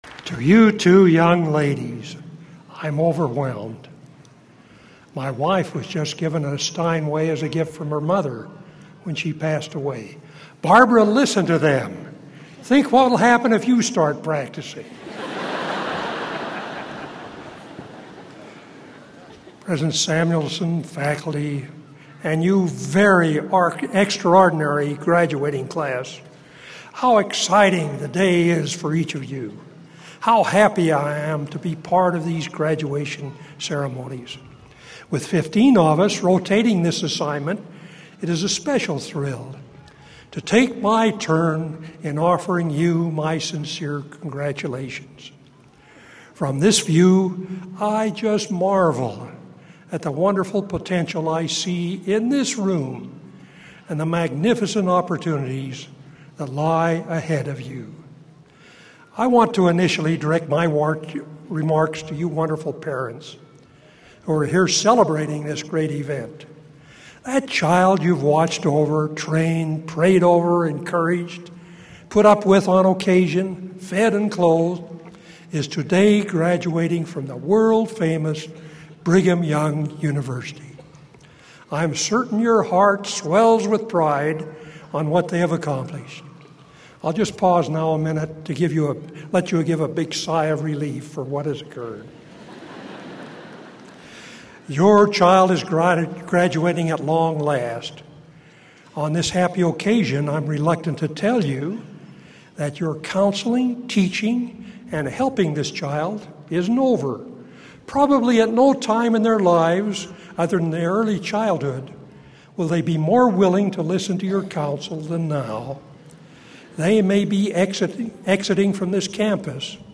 Commencement